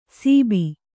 ♪ sībi